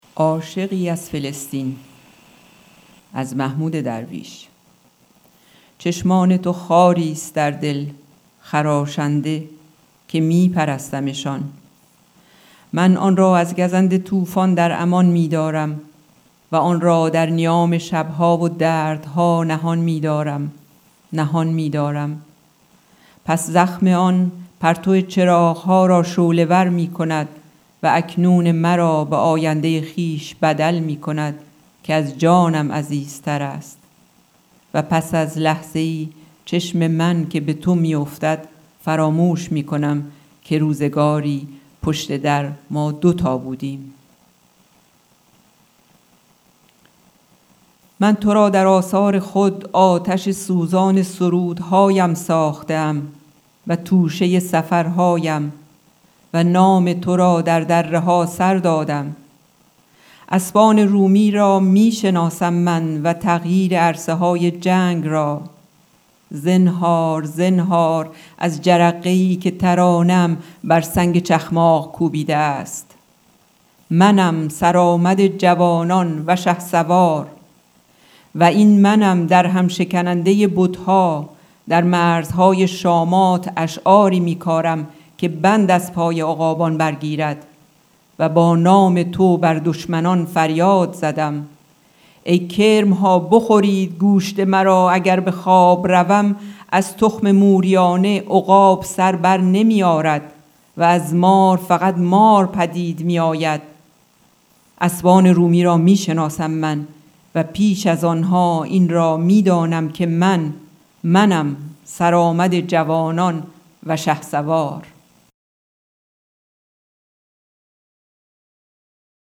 دکلمه اشعاری از محمود درویش
قطعه موسیقی به نام صبرا از ژان مارک فوسا و سیلون گرینو